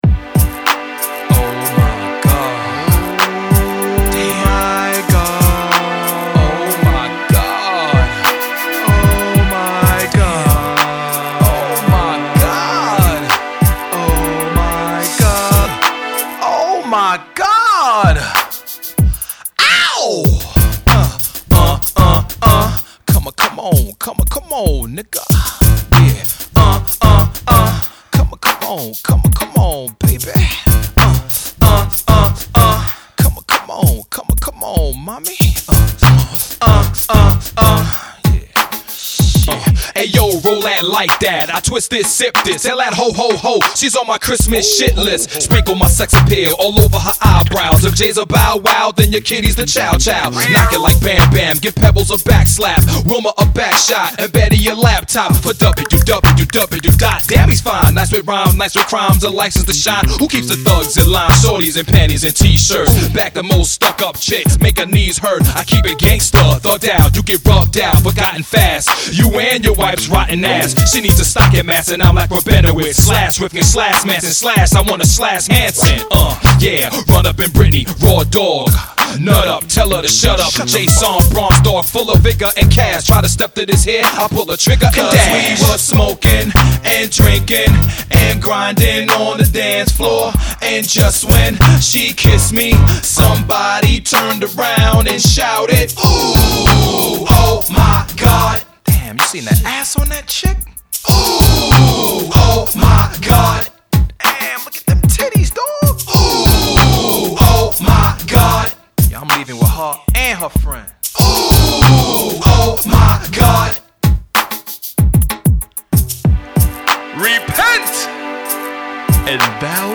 Live tracks recorded in Austin, Texas 2002-2003.